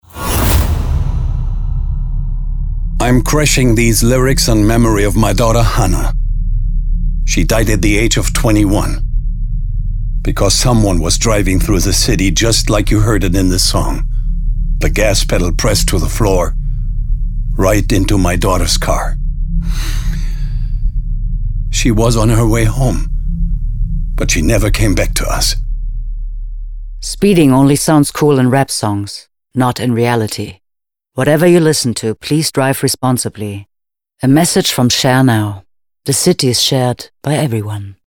L’argent ex æquo  pour 3 spots de la campagne allemande « Crashed Lyrics », une sensibilisation audacieuse et originale à la conduite responsable financée par la société de co-voiturage Share Now. Sur une radio qui a accepté le challenge, les spots étaient diffusés en interruption de chansons dans lesquels la conduite rapide, les comportements à risques, figurent dans les paroles.